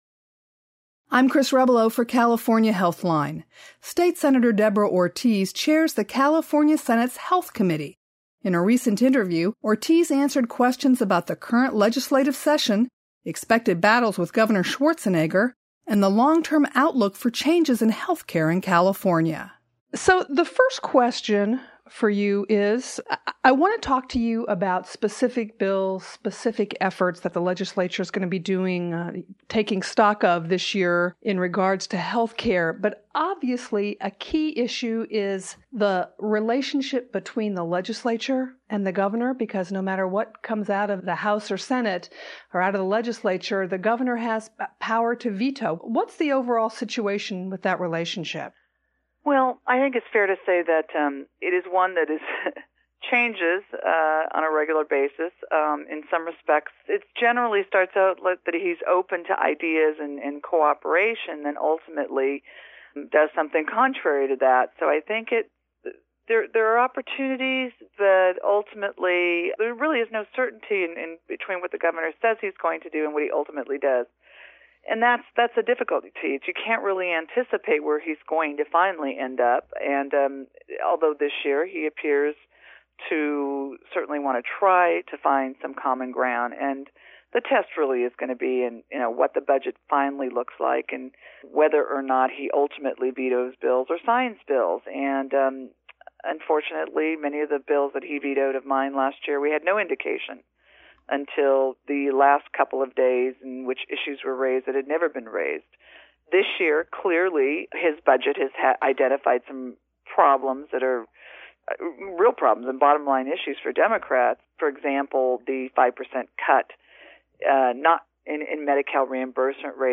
In an interview for a California Healthline special audio report, Senate Health Committee Chair Deborah Ortiz (D-Sacramento) discussed Medi-Cal provider reimbursements, a state Department of Public Health and other health care issues that she expects the Legislature to take up in 2006.